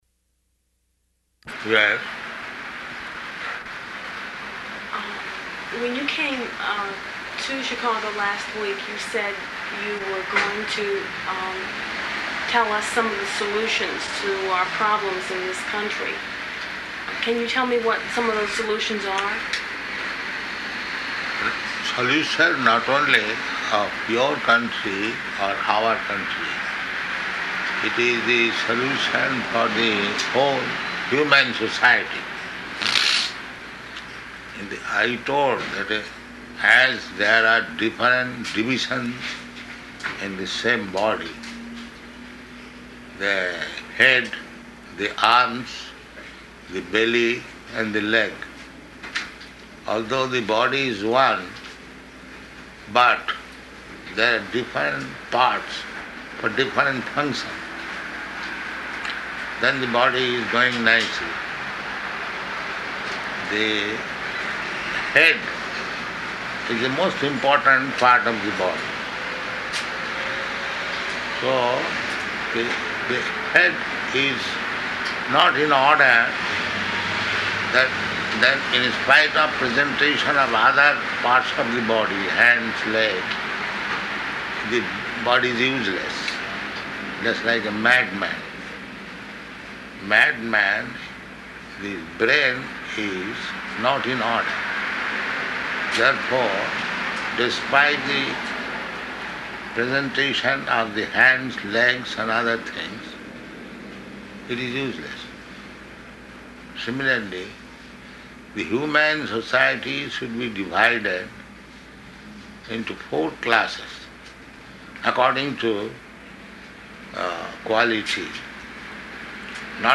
Television Interview
Television Interview --:-- --:-- Type: Interview Dated: July 9th 1975 Location: Chicago Audio file: 750709IV.CHI.mp3 Prabhupāda: Yes?